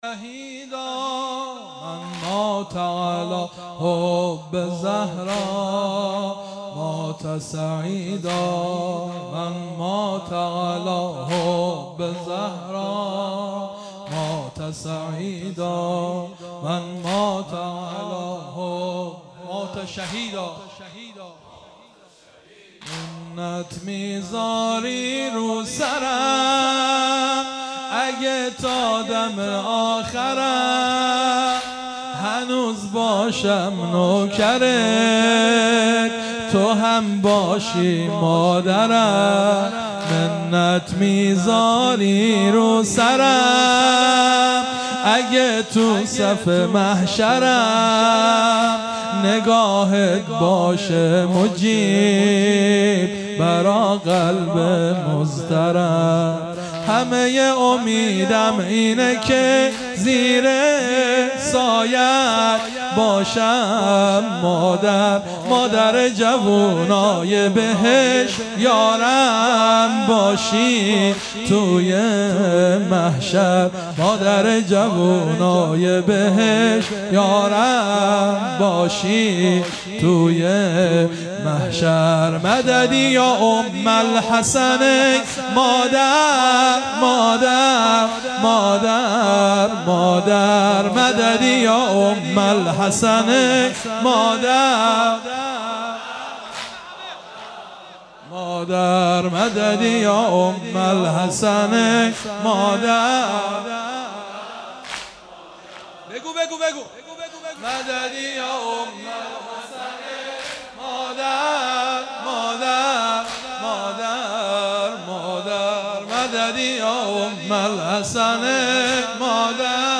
خیمه گاه - هیئت بچه های فاطمه (س) - واحد | منت میذاری رو سرم
فاطمیه اول | هیئت انصار ولایت دارالعباده یزد